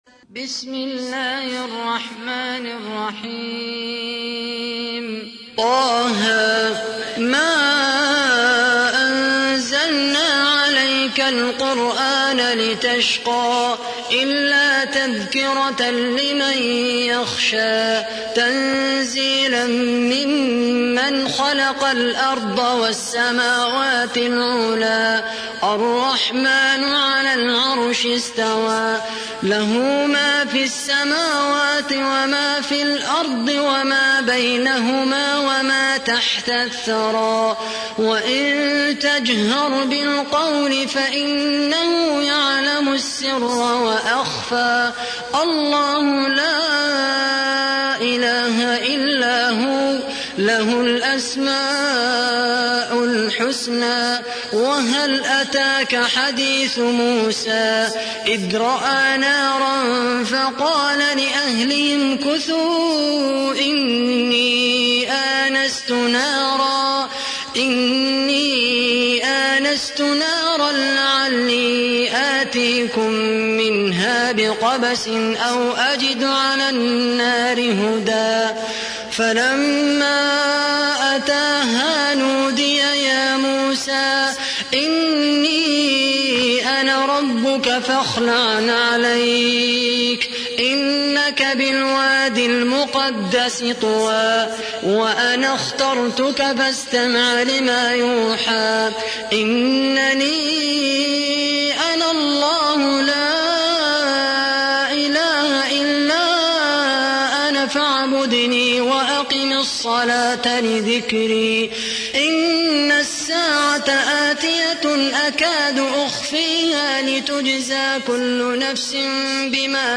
تحميل : 20. سورة طه / القارئ خالد القحطاني / القرآن الكريم / موقع يا حسين